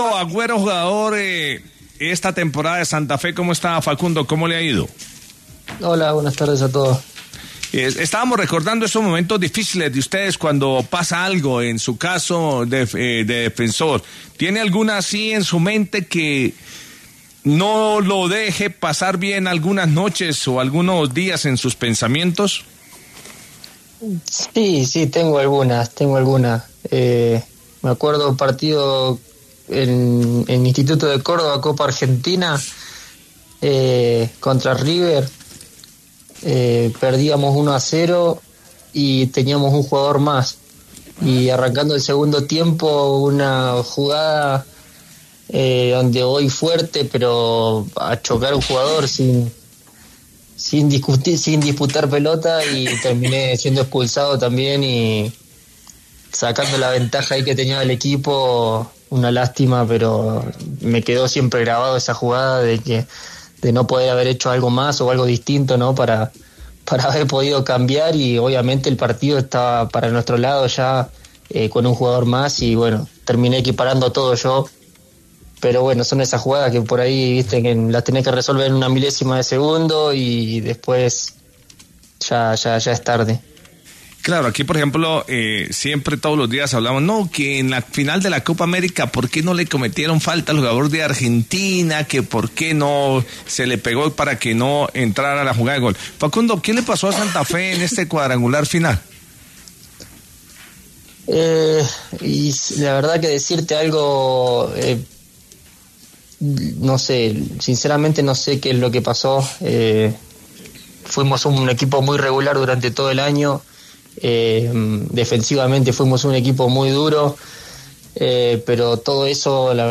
En medio de este escenario y en exclusiva con El Vbar de Caracol Radio, el defensor Facundo Agüero habló sobre el rendimiento del conjunto rojo en las fechas finales.